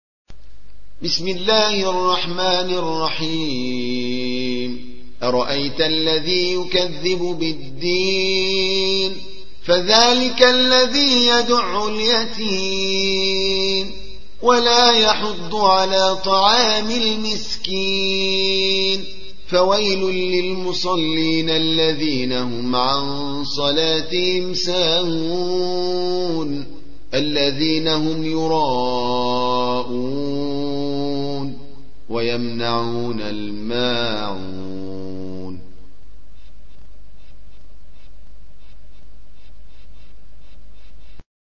107. سورة الماعون / القارئ